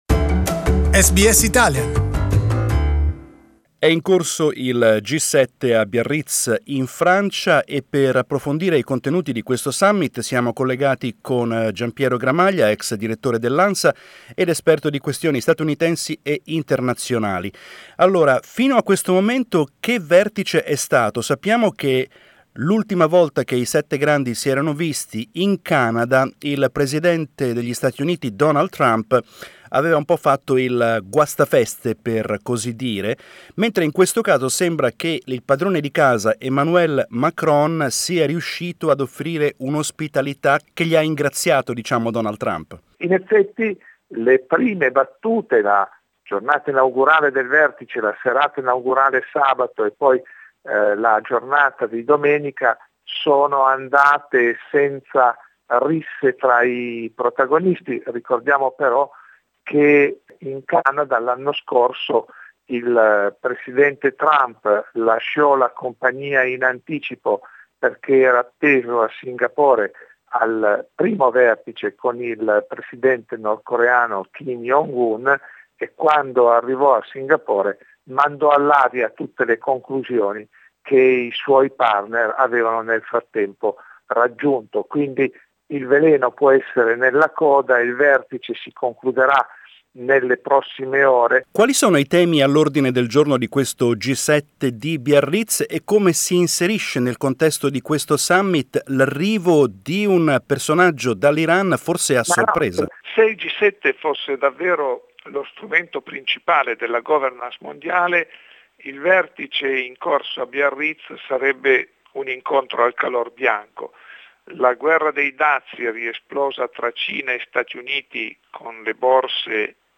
Veteran Italian journalist